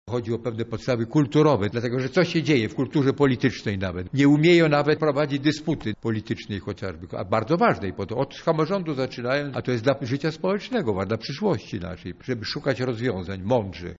Kultura polityczna na wykładzie historycznym
Profesor Jerzy Kłoczowski przedstawił swój wykład o pochwale historii, czyli o zawodzie historyka w XXI wieku.